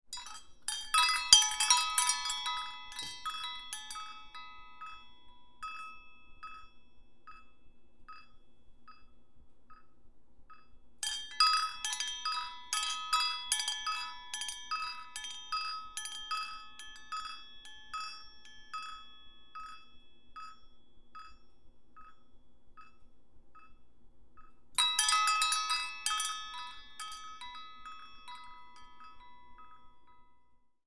Звуки коровьего колокольчика
На этой странице собраны звуки коровьего колокольчика — натуральные и атмосферные записи, которые перенесут вас на деревенское пастбище.